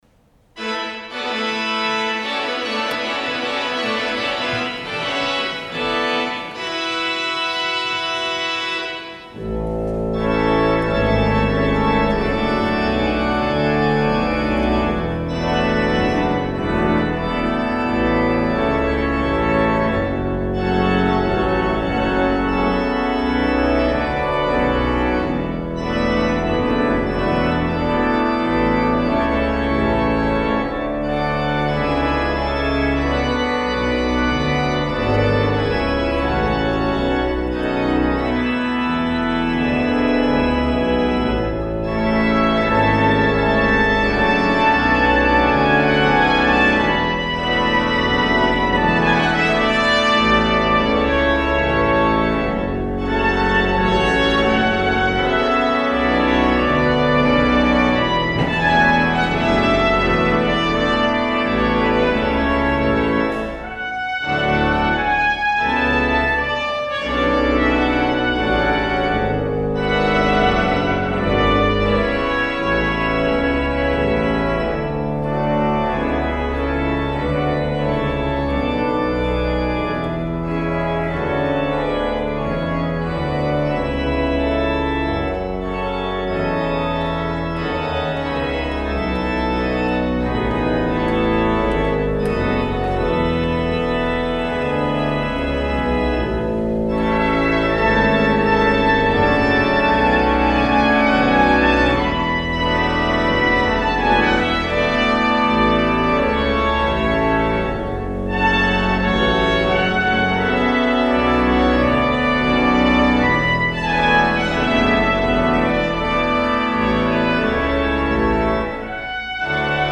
Organ and Trumpet or Organ alone